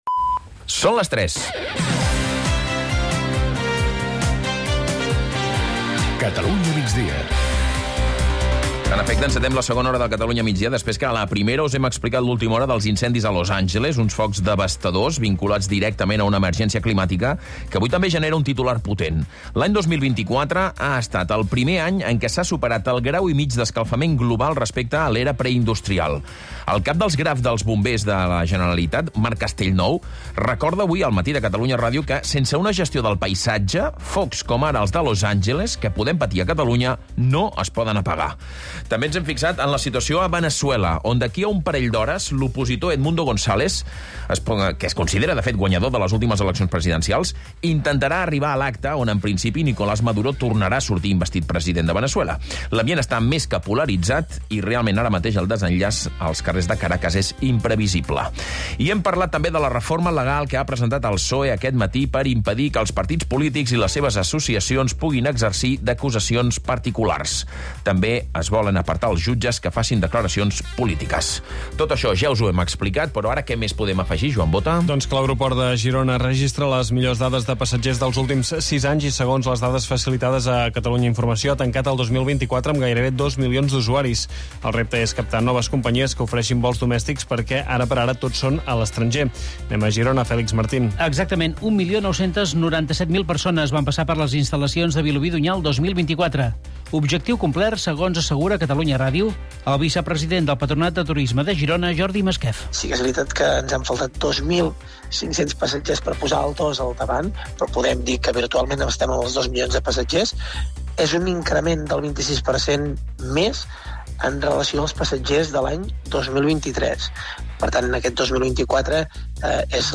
… continue reading 503 episod # Society # Corporaci Catalana de Mitjans Audiovisuals, SA # Catalunya Rdio # News Talk # News